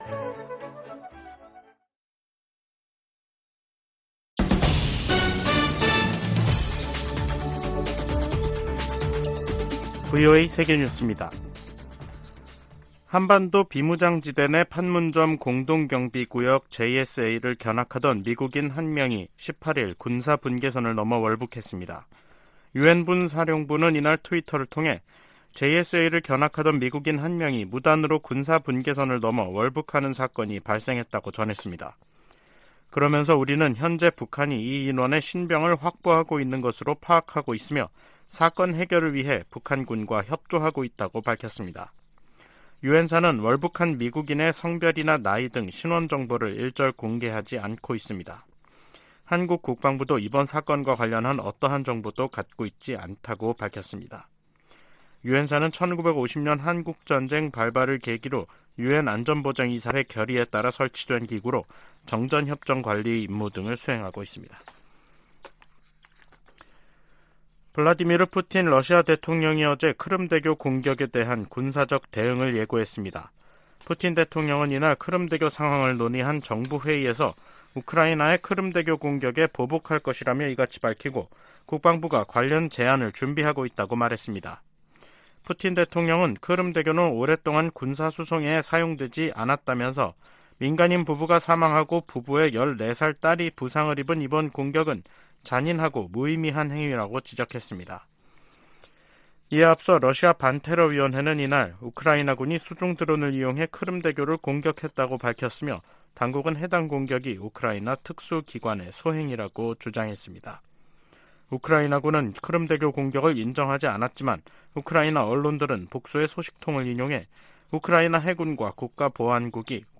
VOA 한국어 간판 뉴스 프로그램 '뉴스 투데이', 2023년 7월 18일 2부 방송입니다. 미국과 한국은 18일 서울에서 핵협의그룹(NCG) 첫 회의를 갖고 북한이 핵 공격을 할 경우 북한 정권은 종말을 맞을 것이라며, 확장억제 강화의지를 재확인했습니다. 미 국무부는 북한의 도발에 대한 유엔 안보리의 단합된 대응을 촉구했습니다. 아세안지역안보포럼(ARF)이 의장성명을 내고, 급증하는 북한의 탄도미사일 발사가 역내 평화를 위협한다고 비판했습니다.